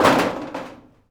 metal_sheet_impacts_13.wav